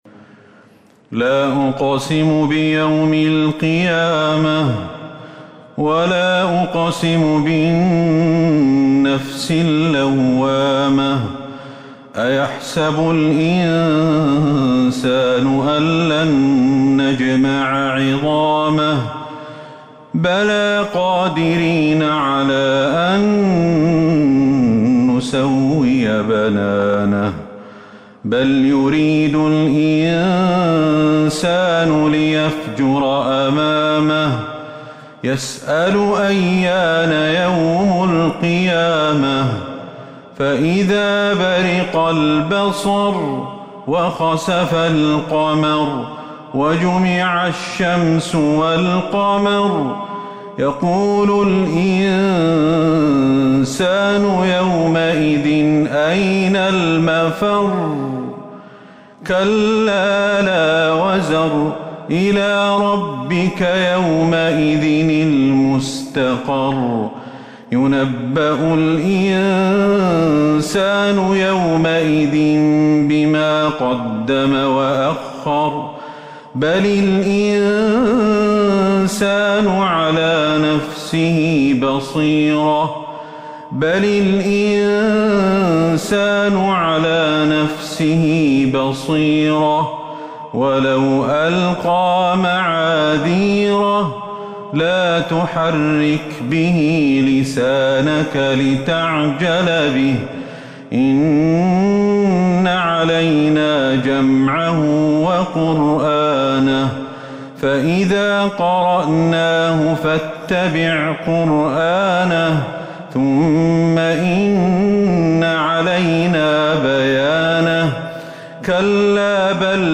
سورة القيامة Surat Al-Qiyamah من تراويح المسجد النبوي 1442هـ > مصحف تراويح الحرم النبوي عام 1442هـ > المصحف - تلاوات الحرمين